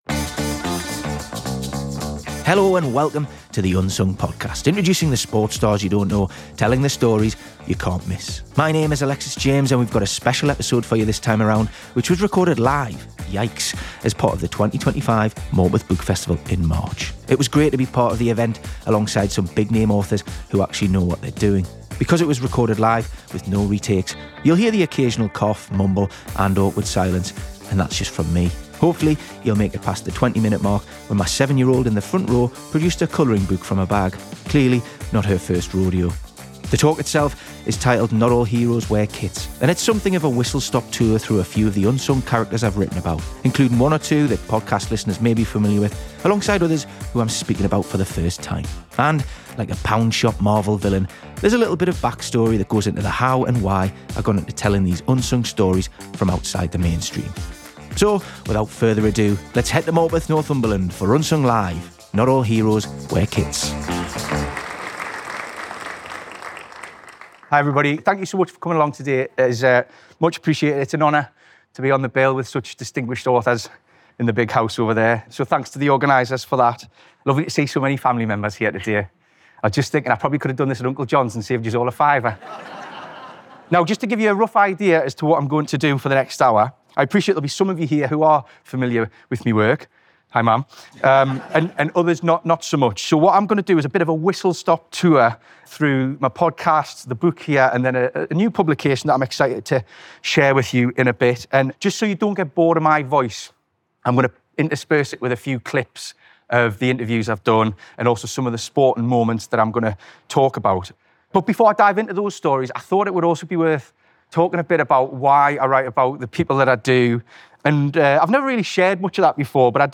We’ve got a special episode for you this time around, which was recorded live as part of the 2025 Morpeth Book Festival in March.